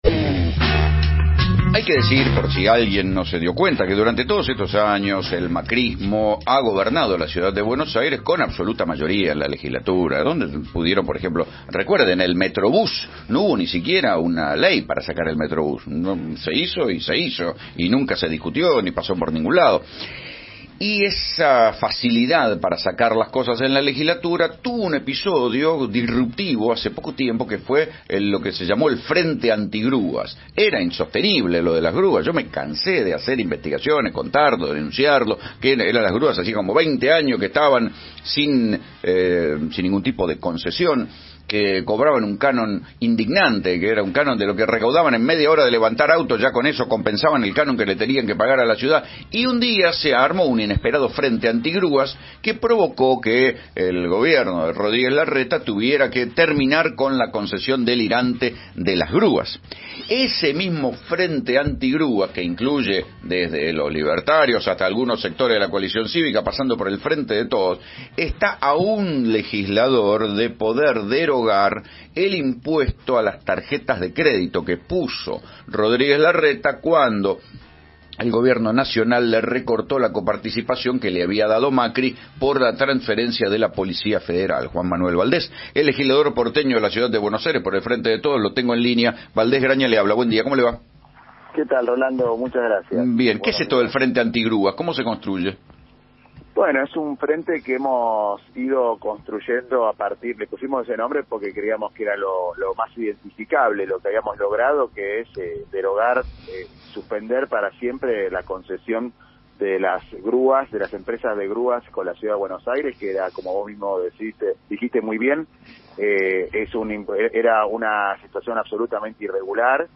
El legislador porteño por el Frente de Todos dialogó con Rolando Graña sobre el Frente Anti-Grúas conformado por diversos bloques opositores y el proyecto para eliminar el impuesto del GCBA sobre el uso de tarjetas de crédito.
Entrevista-a-Juan-Manuel-Valdes-en-dialogo-con-Rolando-Grana.mp3